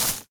default_grass_footstep.2.ogg